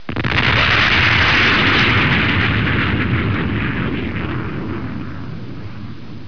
دانلود آهنگ طیاره 64 از افکت صوتی حمل و نقل
دانلود صدای طیاره 64 از ساعد نیوز با لینک مستقیم و کیفیت بالا
جلوه های صوتی